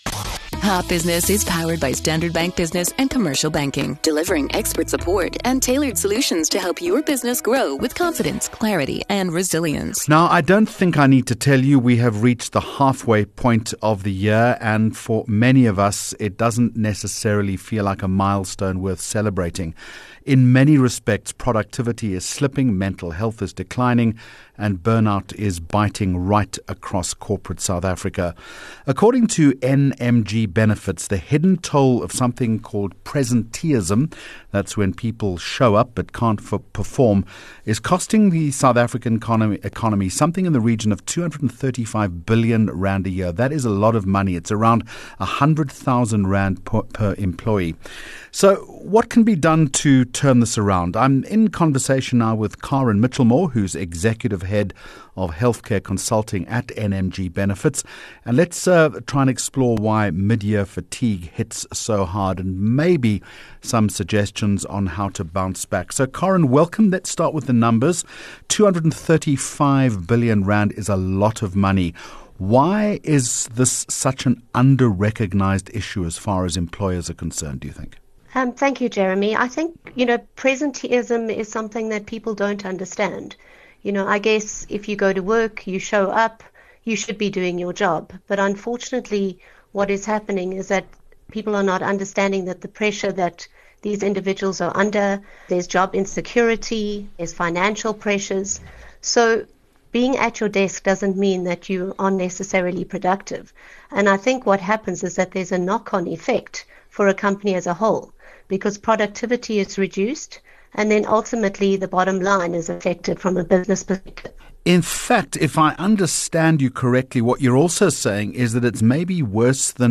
Consumer Insights Topic: Why mid-year fatigue is hitting hard and what can help Guest